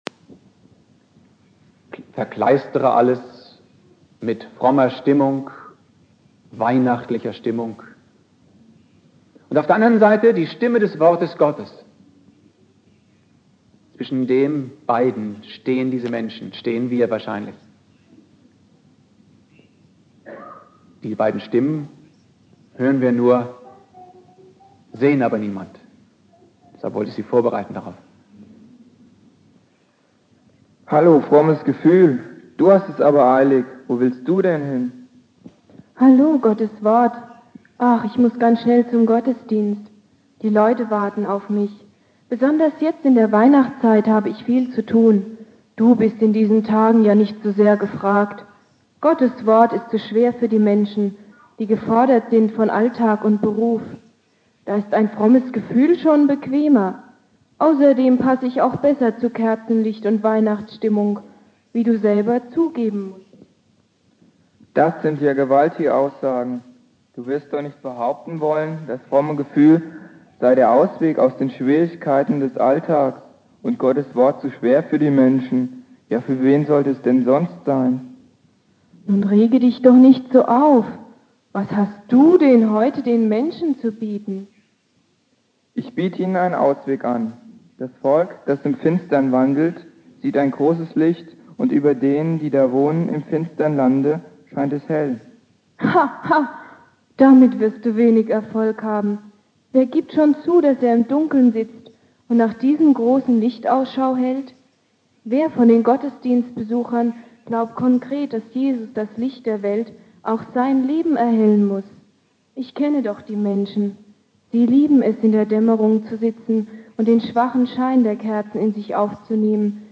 Predigt
Heiligabend Prediger